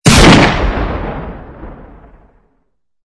Sniper Rifle Shoot.wav